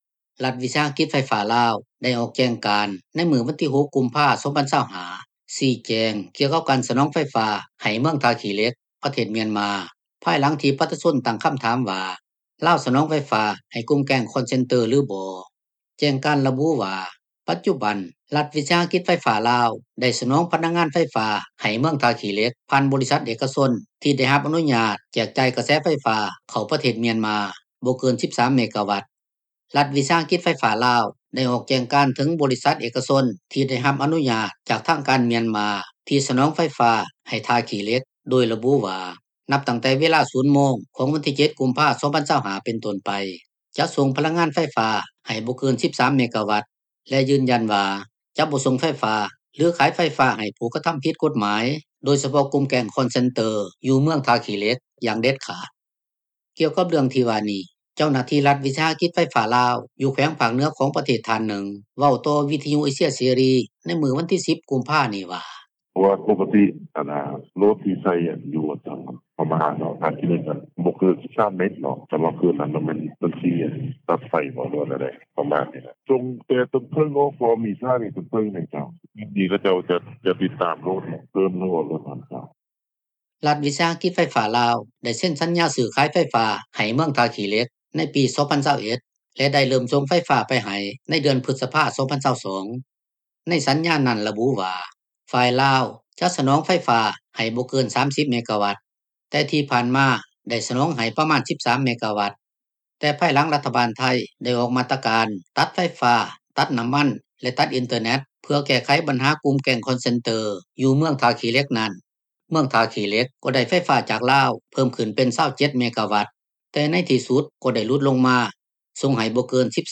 ກ່ຽວກັບເລື່ອງທີ່ວ່ານີ້ ເຈົ້າໜ້າທີ່ລັດວິສາຫະກິດໄຟຟ້າລາວ ຢູ່ແຂວງພາກເໜືອ ຂອງປະເທດ ທ່ານໜຶ່ງ ເວົ້າຕໍ່ວິທຍຸເອເຊັຽເສຣີ ໃນມື້ວັນທີ 10 ກຸມພາ ນີ້ວ່າ: